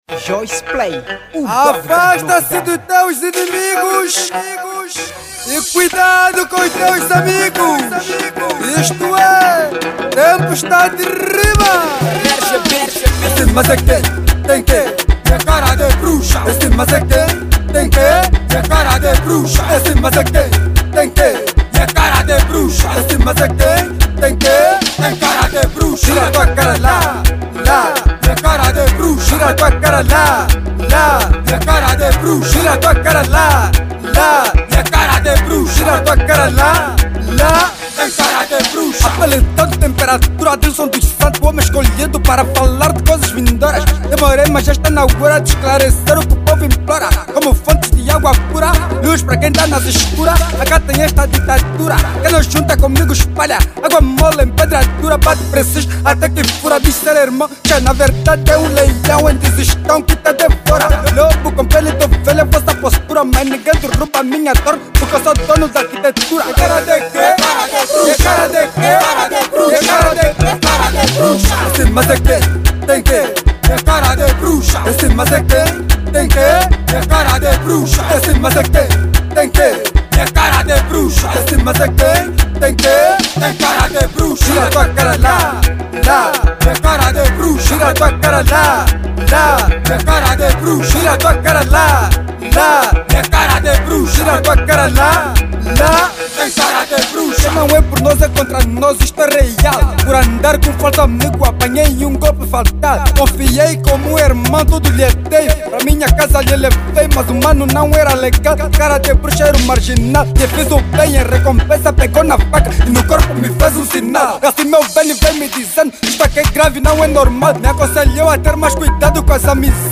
Categoria: Kuduro